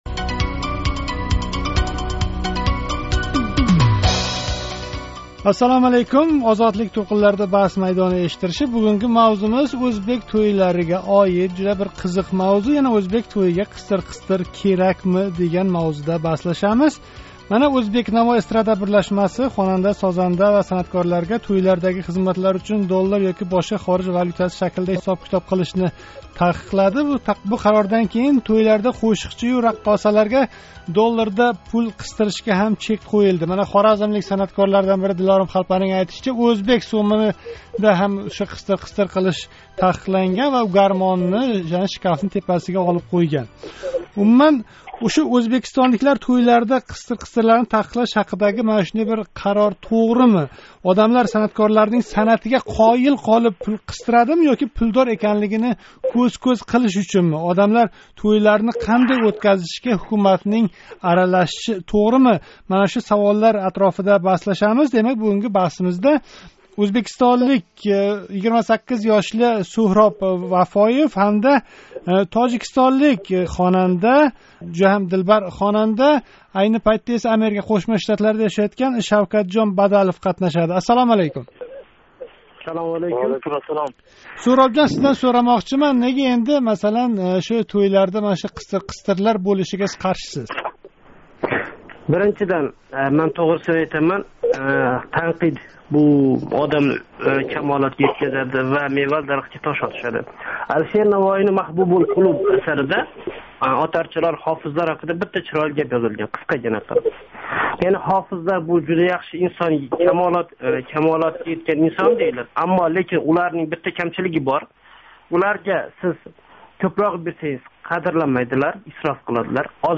Озодликнинг “Баҳс майдони” эшиттиришининг навбатдаги сонида ўзбек тўйларида санъаткорларга пул қистириш тарафдорлари ва бунга қаршилар баҳслашди.